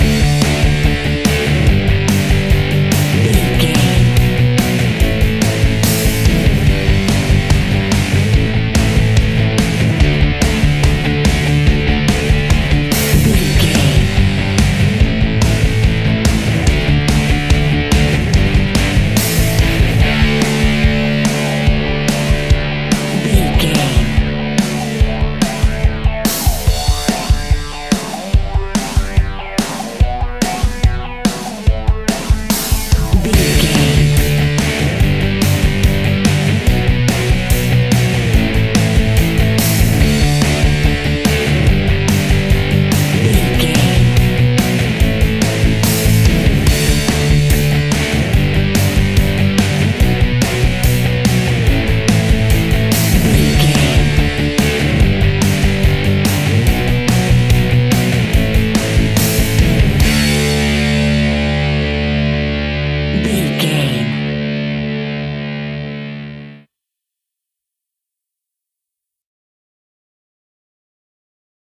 Epic / Action
Fast paced
Ionian/Major
D
hard rock
blues rock
distortion
punk metal
rock instrumentals
Rock Bass
heavy drums
distorted guitars
hammond organ